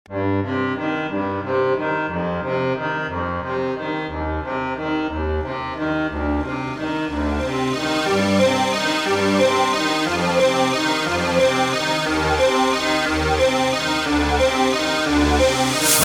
全体的にゲームBGM色溢れるものになっていますので、ゲーム好きの方ならきっと気に入ってくれるはずです。